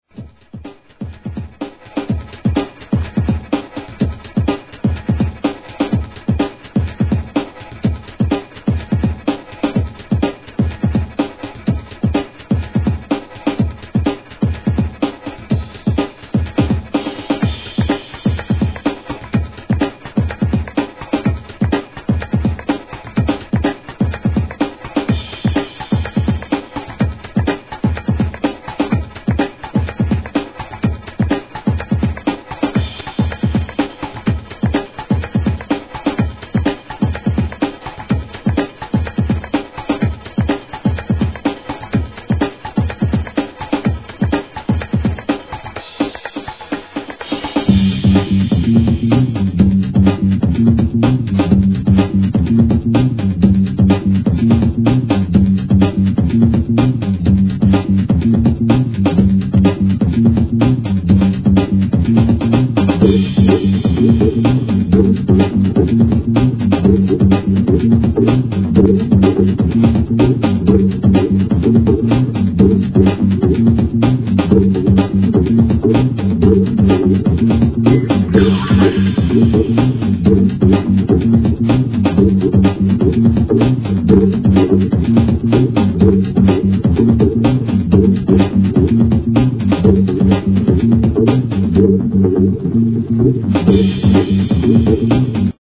1999年、FUNKY且つNEW SCHOOLなブレイクビーツ物!!